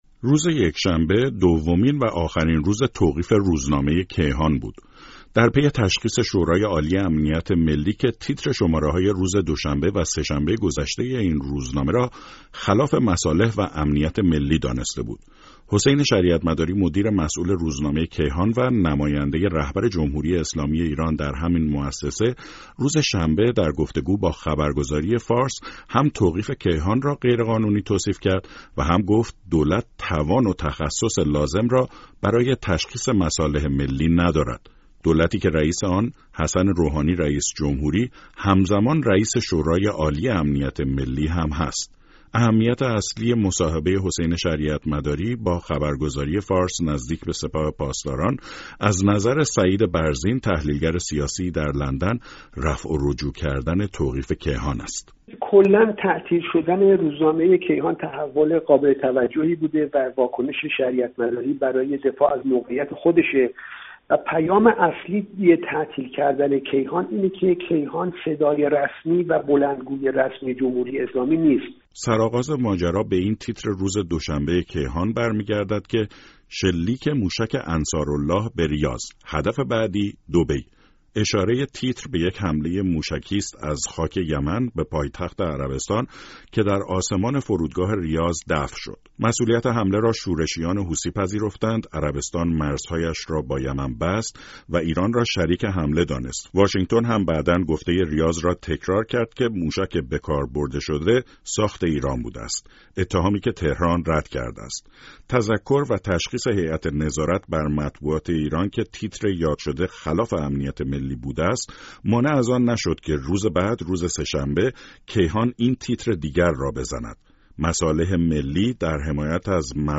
رادیو فردا نظر دو حقوقدان و یک ناظز سیاسی را درباره واکنش حسین شریعتمداری مدیر مسئول کیهان و انجمن روزنامه نگاران مسلمان به توقیف موقت این روزنامه جویا شده است.